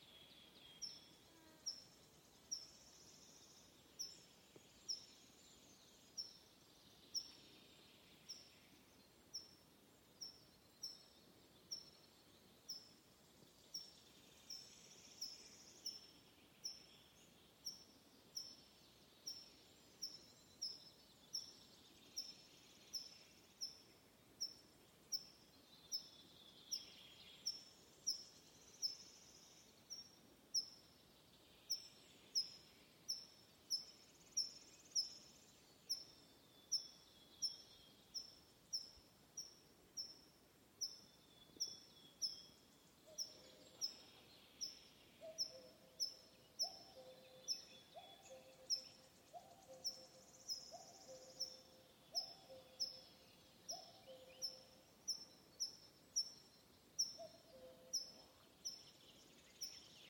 Birds -> Pipits ->
Tree Pipit, Anthus trivialis
StatusAgitated behaviour or anxiety calls from adults
NotesVarētu būt plukšķis ar uztraukuma saucienu?